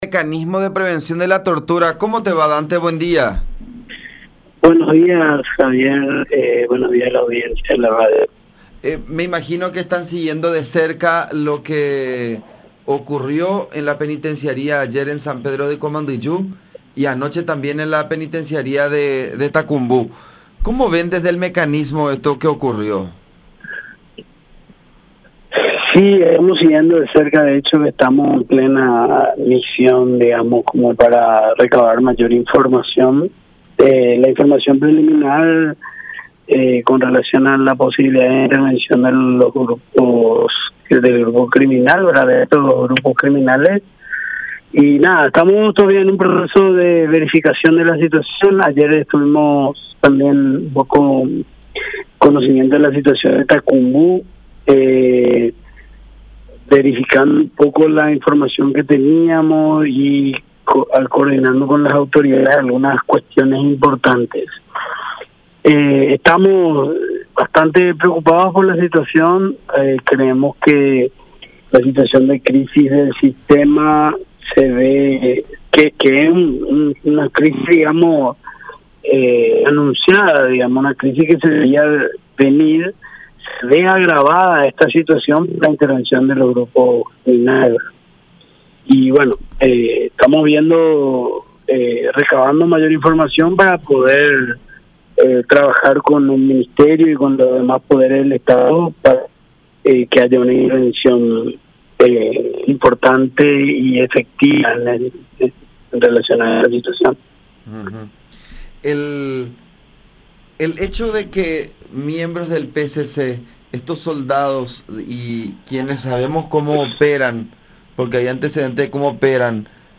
“Es una crisis que se veía venir y ahora se ve agravada”, expresó Dante Leguizamón, comisionado del MNP, en comunicación con La Unión.